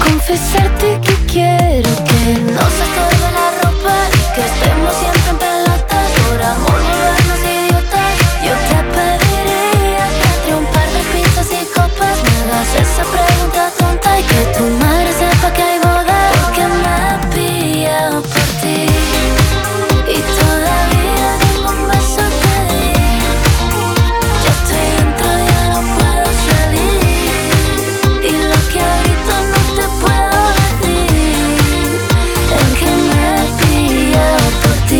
Жанр: Поп
# Pop in Spanish